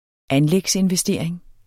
Udtale [ ˈanlεgs- ]